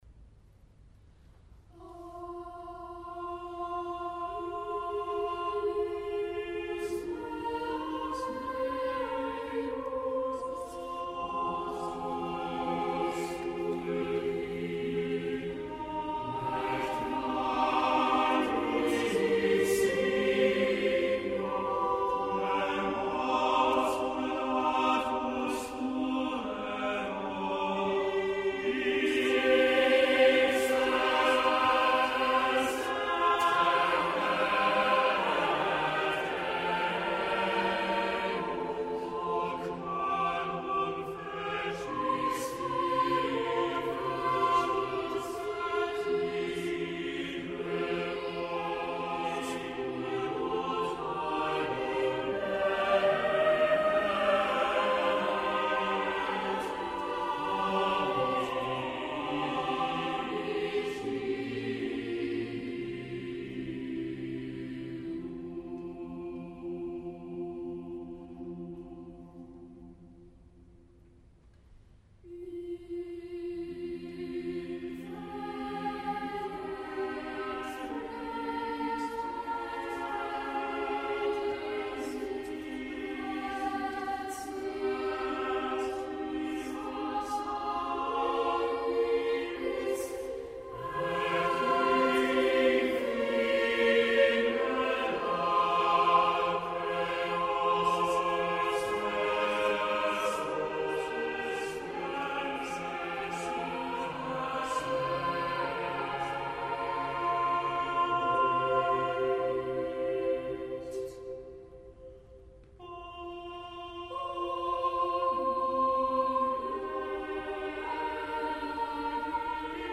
Easter from King’s College, Cambridge
10        Choir: